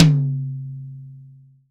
ROCK HI-TOM.WAV